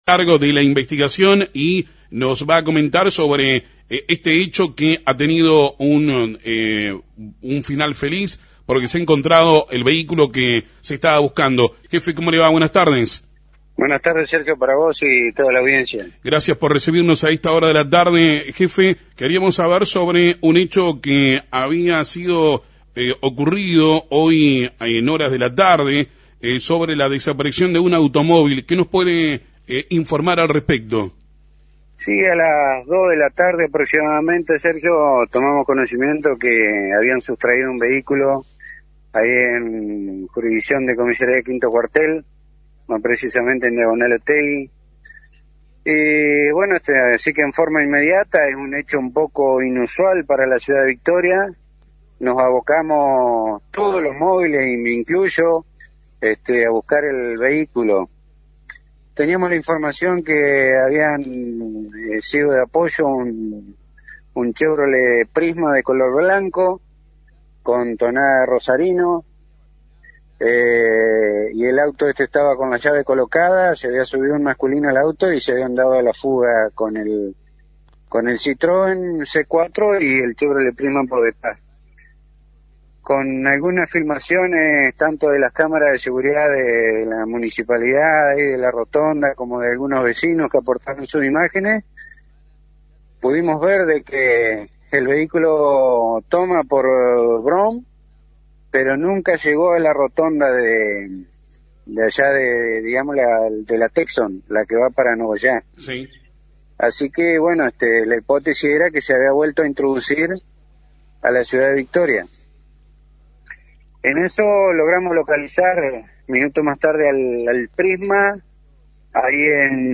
Jefe Departamental de Policía habló sobre el robo del auto en FM 90.3